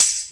Dusty Tamb 02.wav